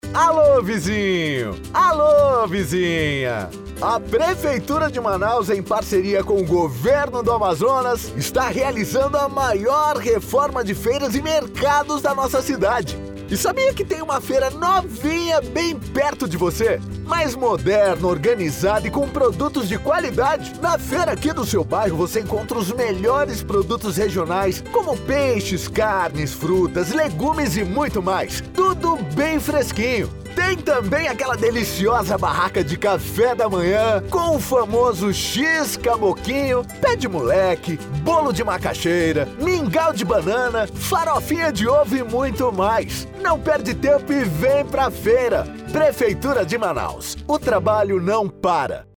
Carro de Som
CARRO-DE-SOM-VT-VEM-PRA-FEIRA.mp3